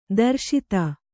♪ darśita